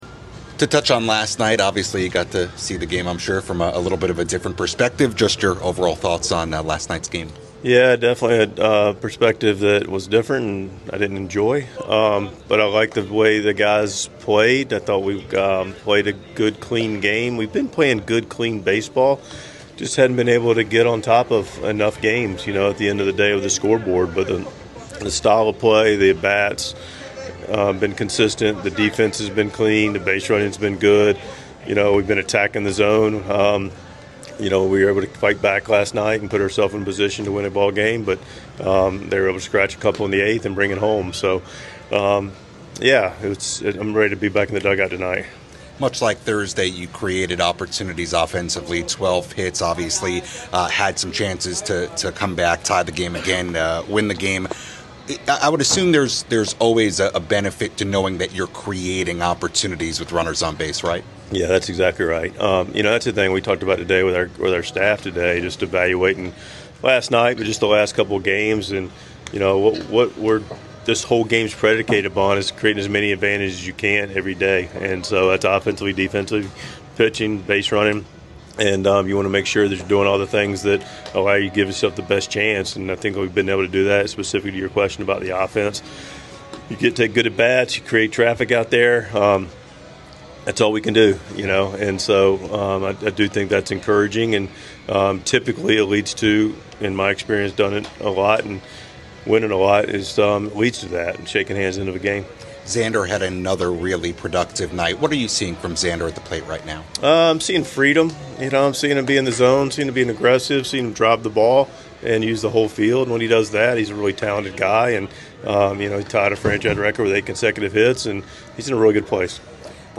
6.21.25 Mike Shildt Pregame Press Conference (June 21 vs. Royals)